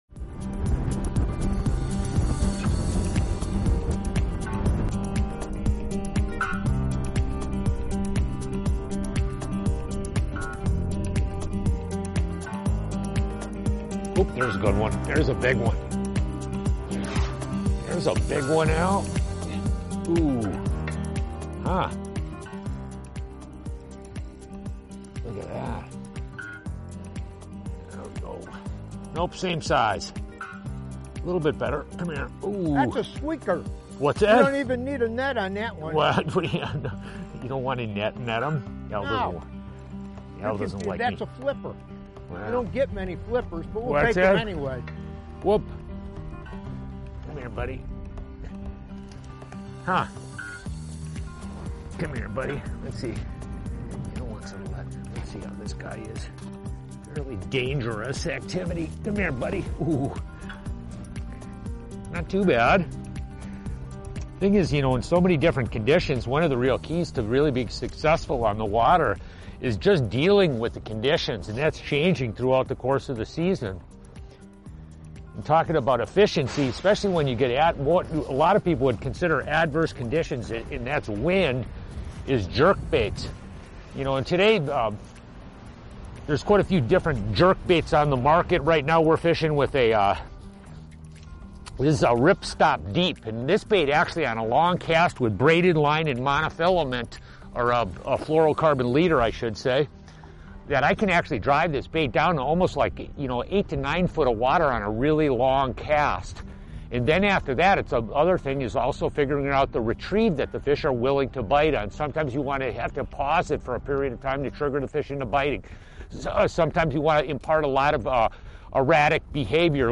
In the midst of various fish sizes and temperaments—some needing a net, others flipping free—the anglers shared light banter and keen attention to fish behavior.